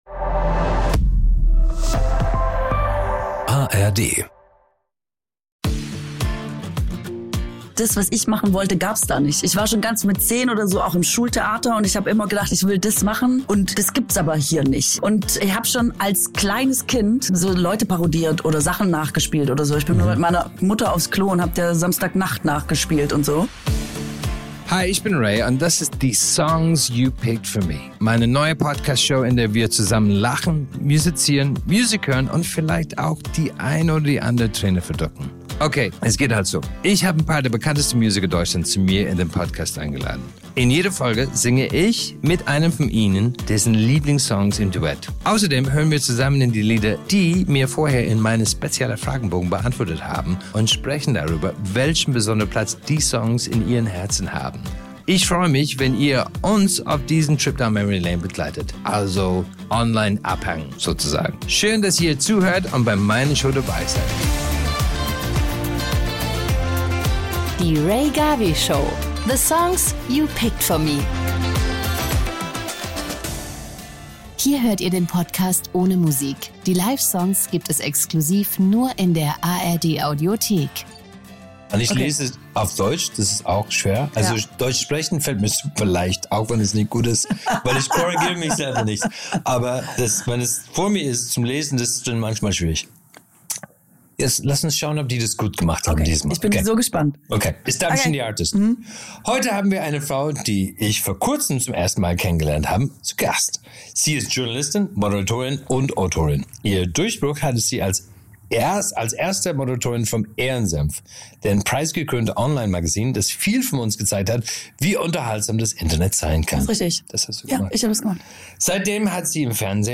Mit ihrem Dialekt sorgt die gebürtige Schwäbin für jede Menge Lacher, während sie mit Rea über schwäbische Sparsamkeit und verrückte Kindheitserinnerungen spricht und ihm ihre streng geheimen Modetipps verrät.
Spontan greift Katrin zum Saxofon und wir sagen mal so: So habt ihr den Song garantiert noch nie gehört.